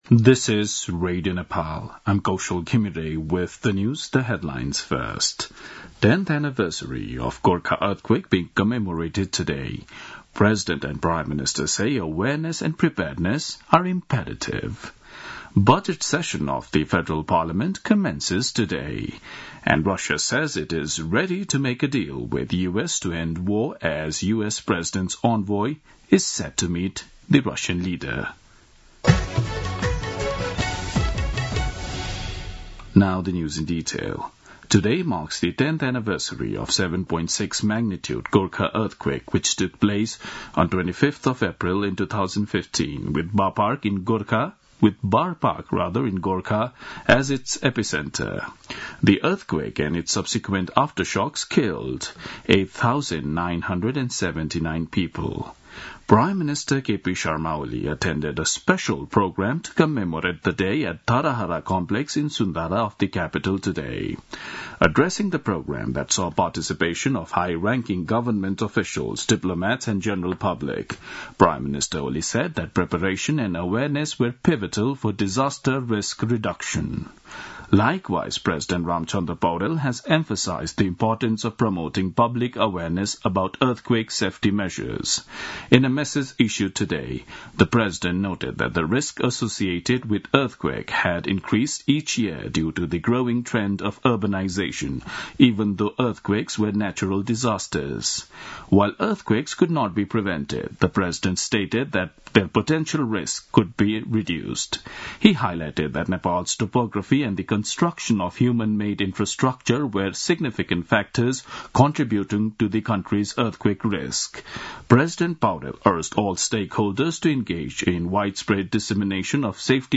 दिउँसो २ बजेको अङ्ग्रेजी समाचार : १२ वैशाख , २०८२